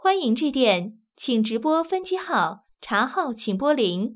ivr-generic_greeting.wav